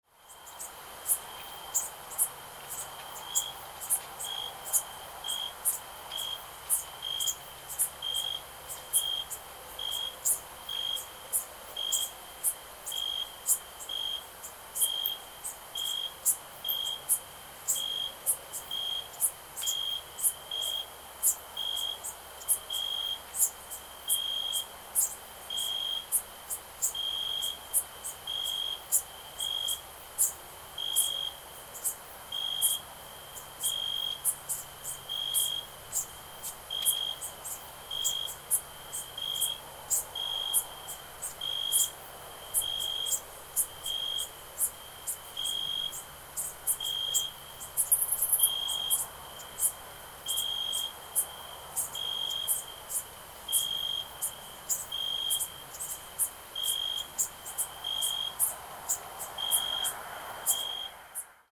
Summer Night Sounds For Sleeping
Authentic recording of a warm summer night. Crickets chirp, insects buzz, and a gentle breeze rustles through the leaves. Perfect natural ambience for relaxation, sleep, or background atmosphere in videos and projects.
Genres: Sound Effects
Summer-night-sounds-for-sleeping.mp3